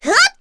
Chrisha-Vox_Attack2_kr.wav